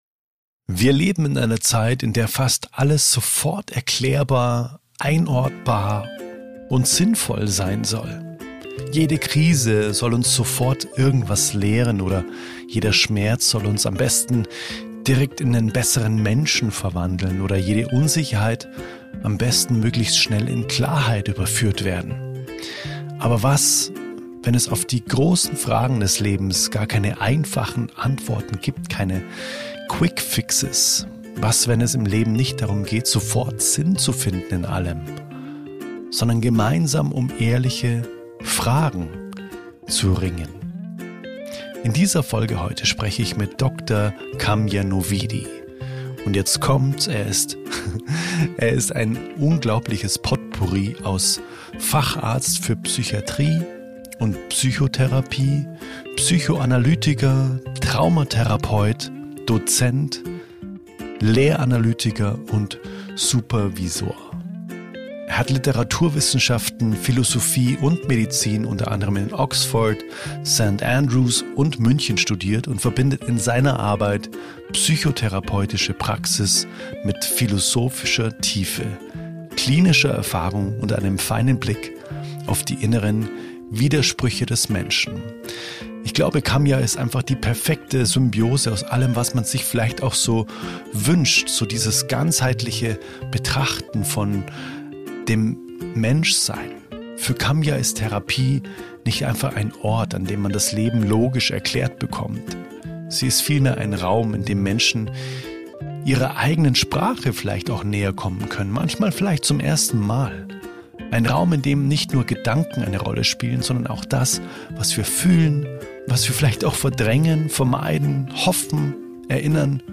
Ausgehend von therapeutischen Erfahrungen, philosophischen Perspektiven und der Figur des Sisyphos sprechen wir über Sinnsuche, Selbsterkenntnis, emotionale Überforderung, Beziehungsmuster und die Kunst, auch ohne endgültige Antworten handlungsfähig zu bleiben. Ein Gespräch über die Müdigkeit des modernen Menschen, über den Druck, sich ständig verstehen und optimieren zu müssen — und über die Möglichkeit, dem Leben wieder etwas einfacher, ehrlicher und menschlicher zu begegnen.